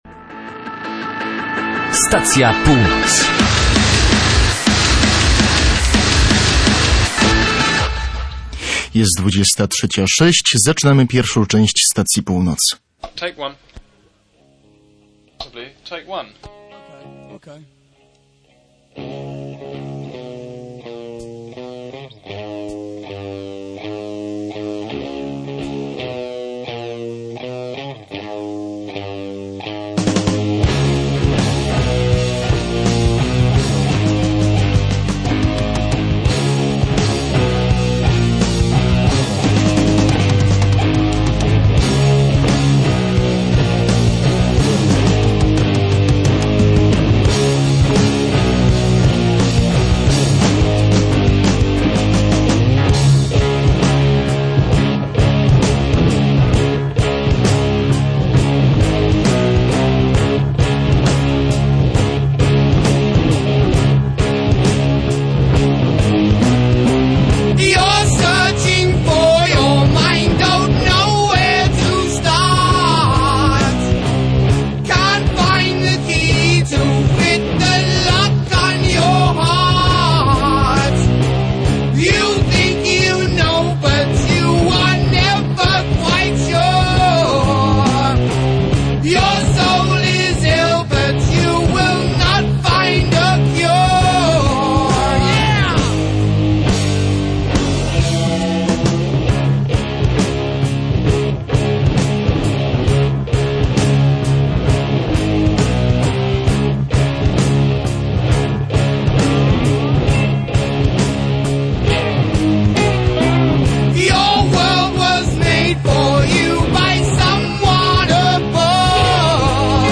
pełna emocji, niekiedy melancholii i spokoju